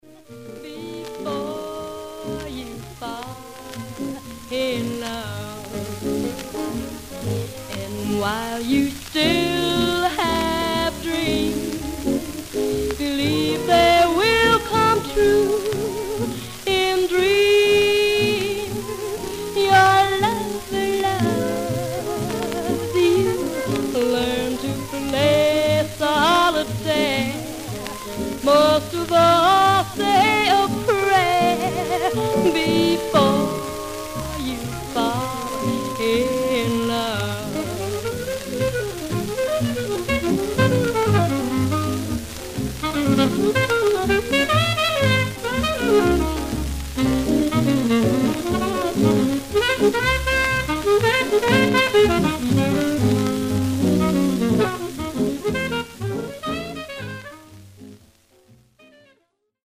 Some surface noise/wear
Mono
Jazz